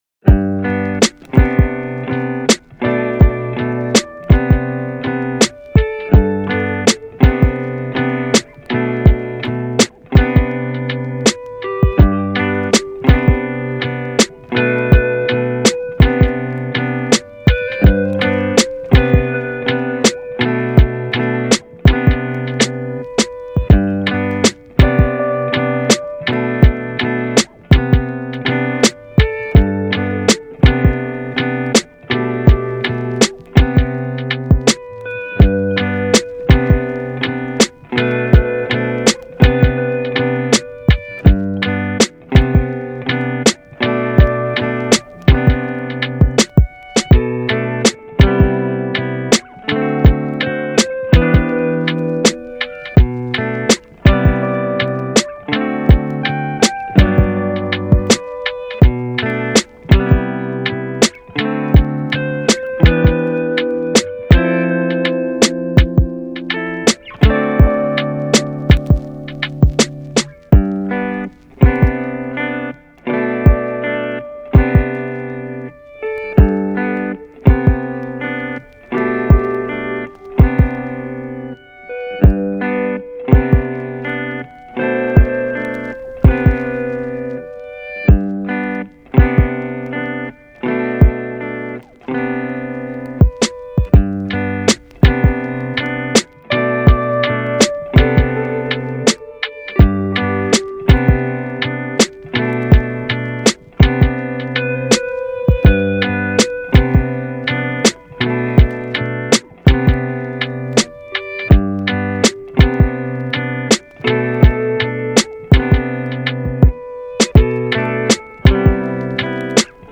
カフェミュージック チル・穏やか フリーBGM